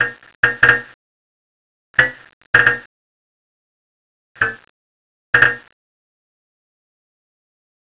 sparks.wav